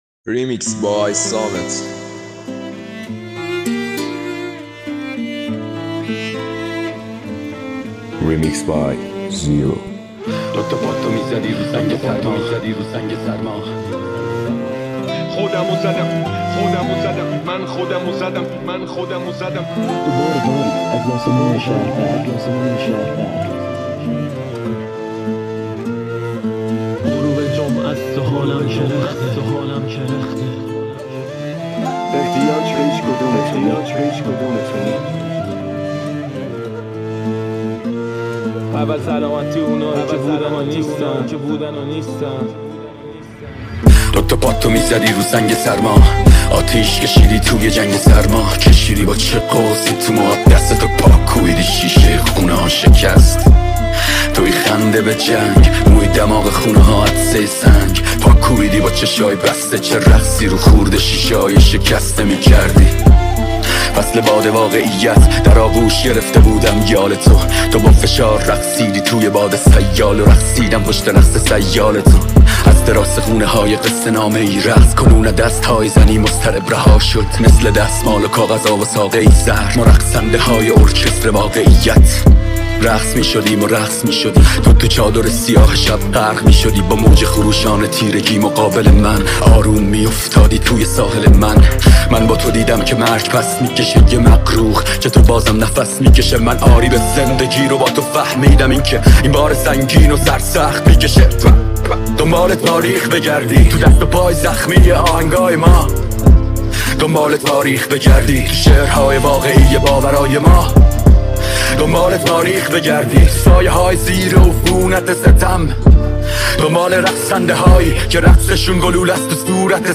ریمیکس شاد
ریمیکس رپ